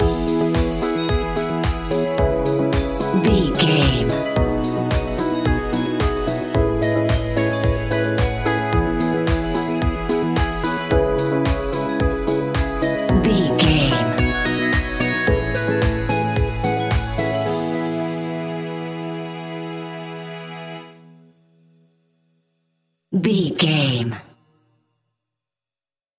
Ionian/Major
peaceful
calm
hopeful
electric piano
synthesiser
drums
strings
electro house
synth bass